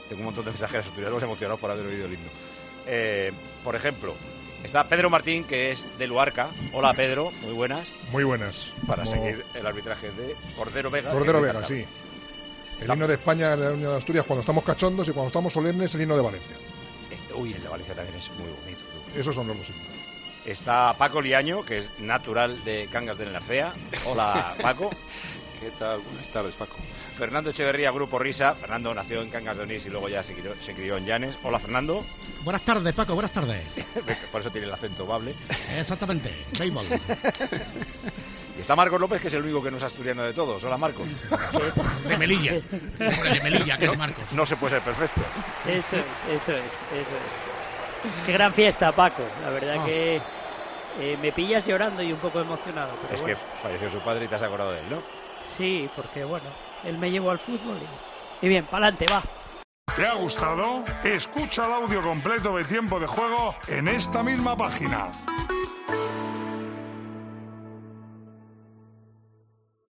Antes de que los 22 protagonistas del partido saltaran al terreno de juego ha aparecido un banda de gaitas que ha interpretado el himno de Asturias en un estadio lleno hasta la bandera que da al partido un aura especial.